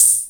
DB - Percussion (12).wav